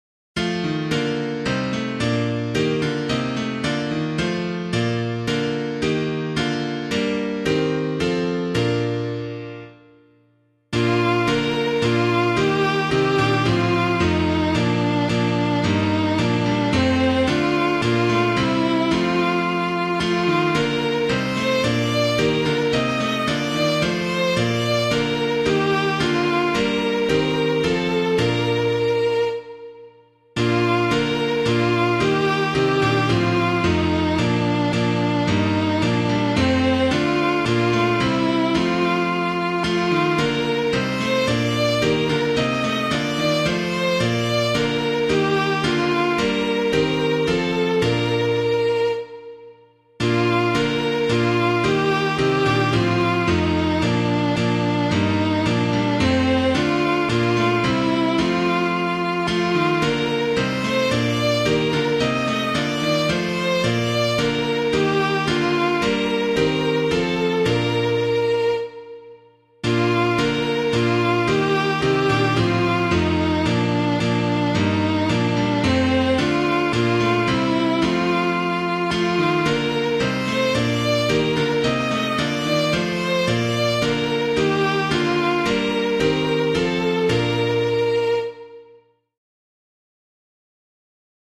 piano 🎤
When Jesus Comes to Be Baptized [Stanbrook Abbey - WINCHESTER NEW] - piano.mp3